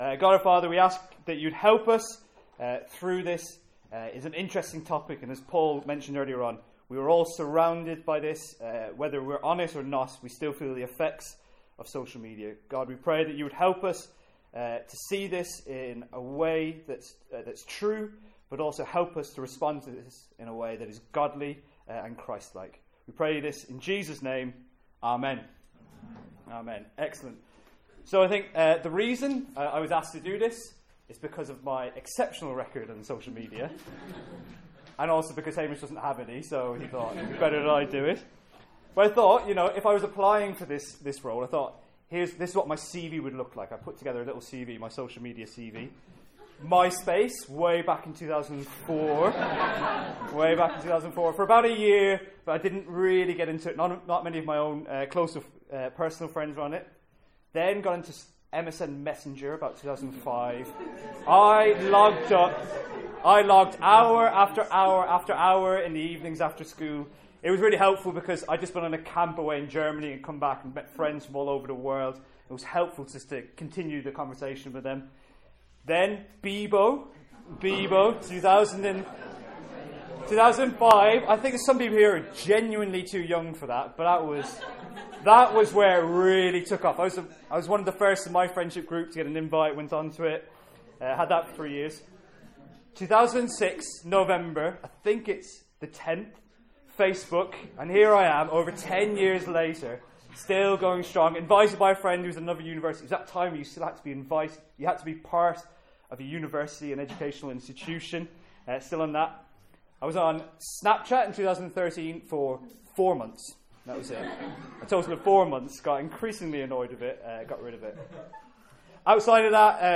From our student lunch on 26th February 2017.